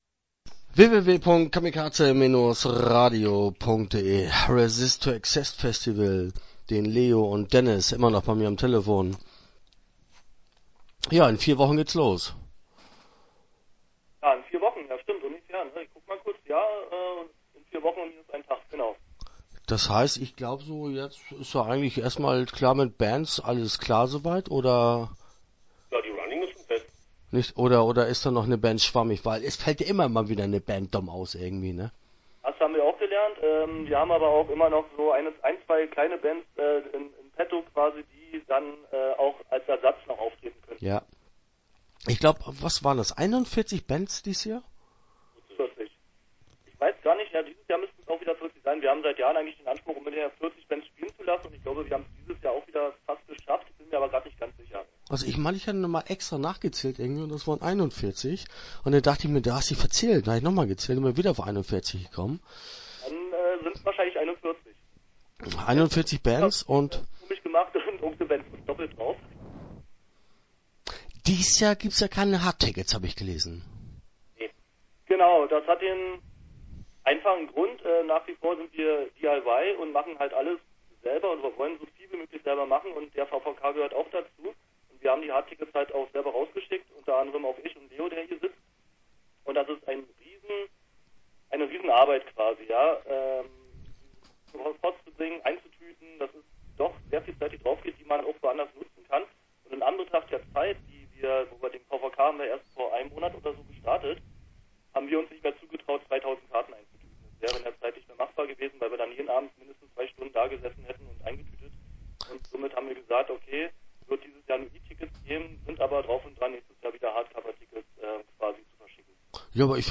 Ressit to Exist - Interview Teil 1 (9:38)